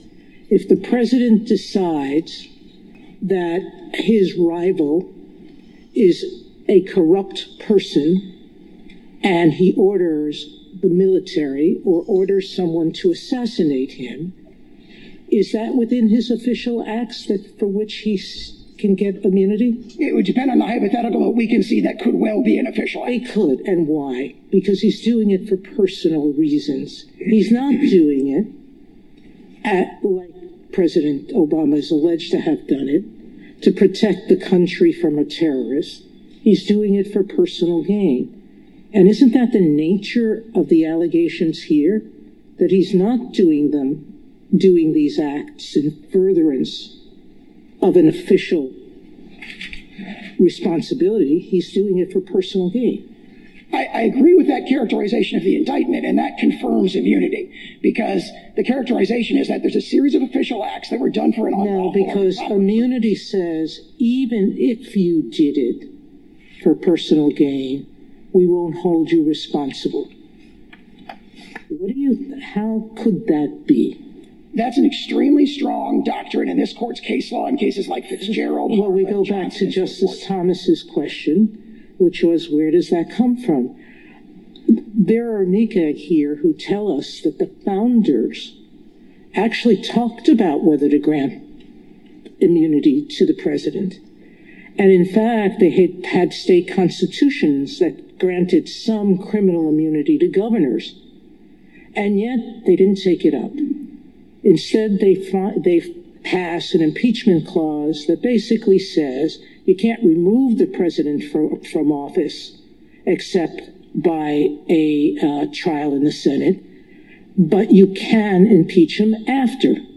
Justice Sonia Sotomayor grilled Sauer on whether ordering execution of a political rival would be considered an official act.